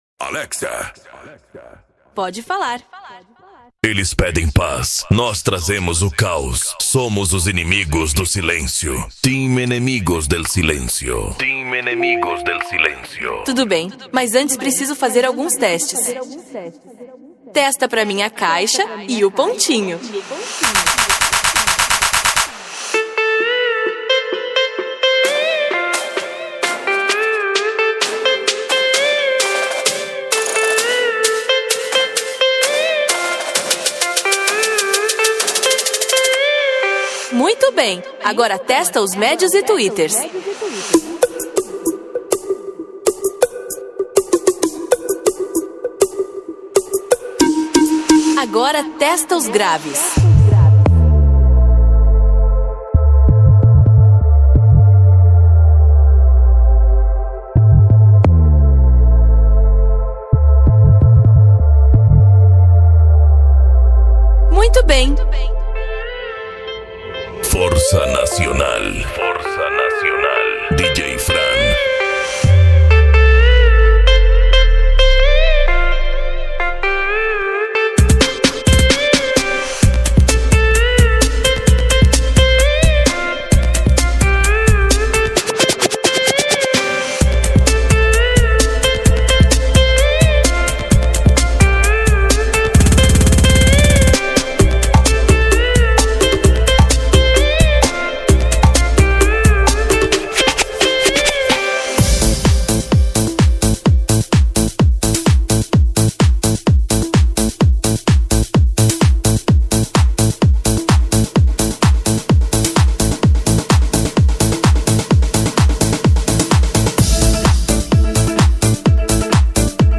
Bass
Mega Funk
Remix